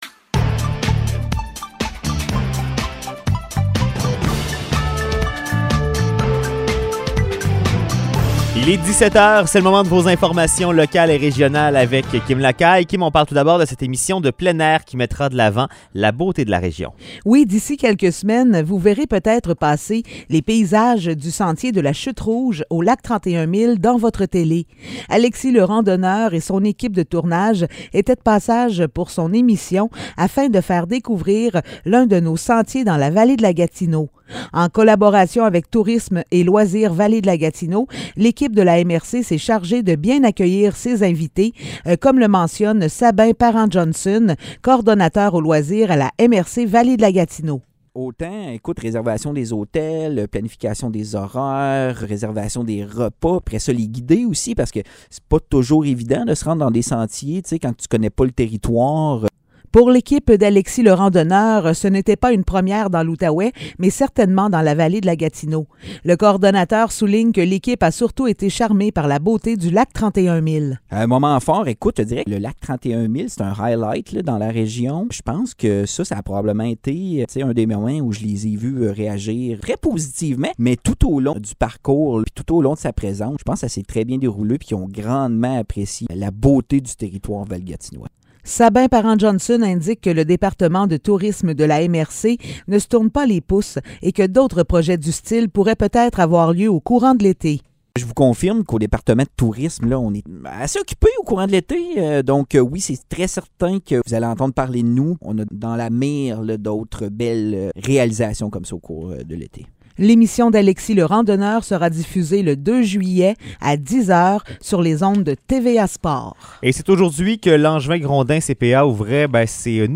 Nouvelles locales - 13 juin 2022 - 17 h